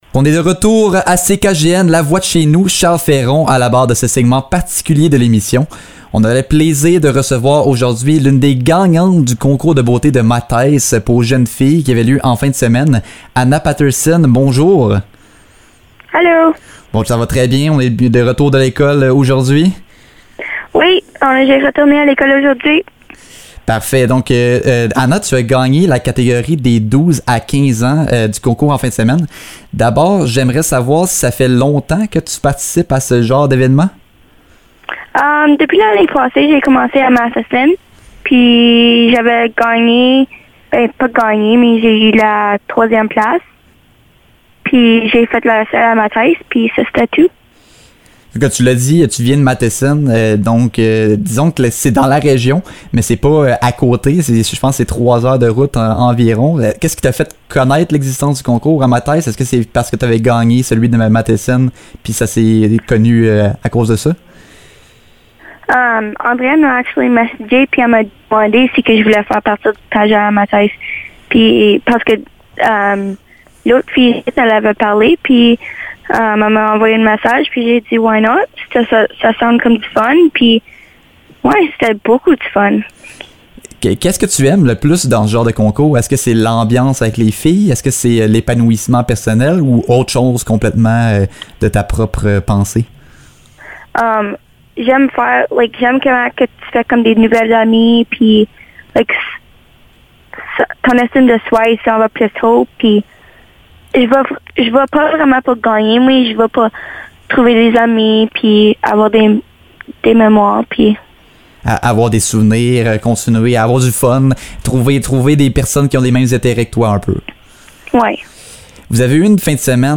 Entrevue-Mattice-Concours-de-beauté-Copie.mp3